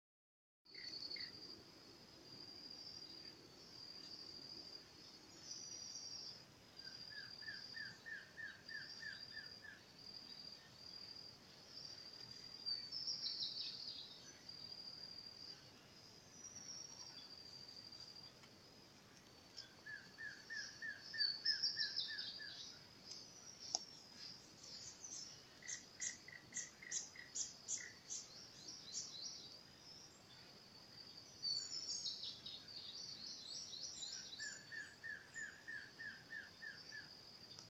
Batará Pecho Negro (Biatas nigropectus)
Nombre en inglés: White-bearded Antshrike
Condición: Silvestre
Certeza: Observada, Vocalización Grabada
Batara-Pecho-Negro-2.mp3